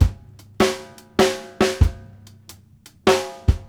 HAT2      -R.wav